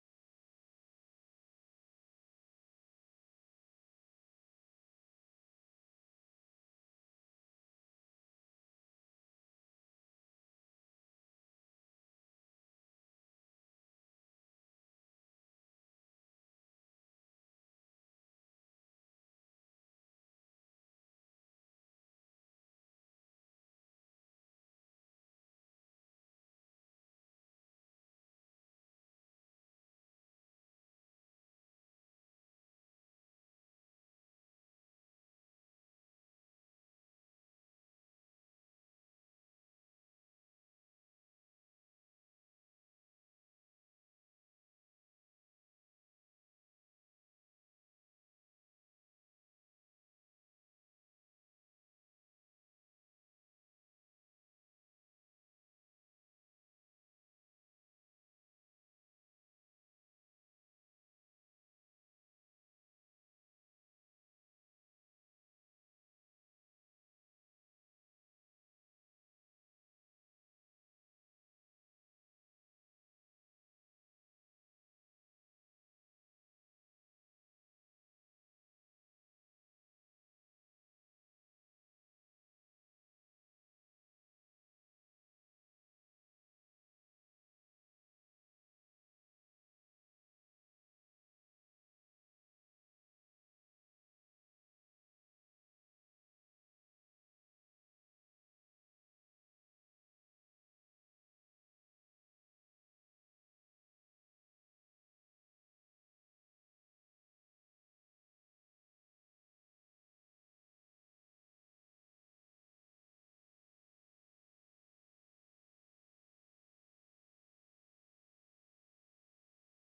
Mp3 Thầy Thích Nhật Từ thuyết giảng Về 3 Sự Thay Đổi Tích Cực ngày 11 tháng 11 năm 2015 (Bản LIVE STREAM) Tường thuật trực tiếp tại chùa Giác Ngộ